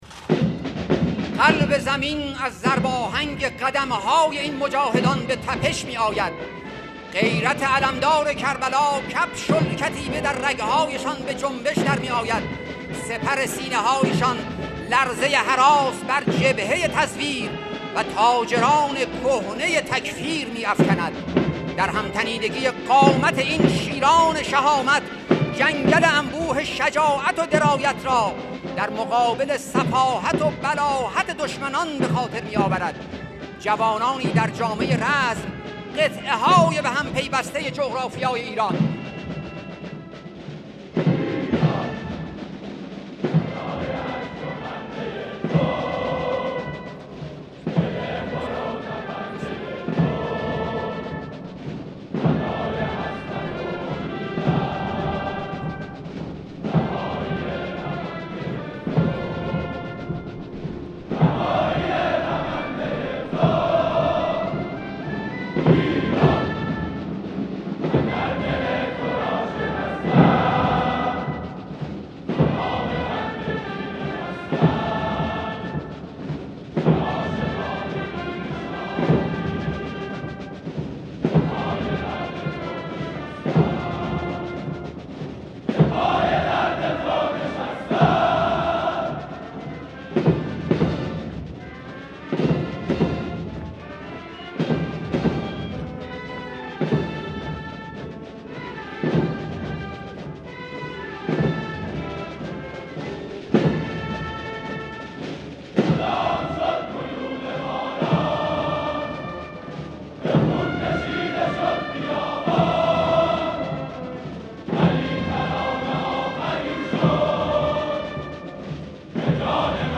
سرود ایران که رهبر انقلاب آنرا تحسین کردند، اجرا شده در مراسم دانشگاه افسری امام علی علیه‌السلام.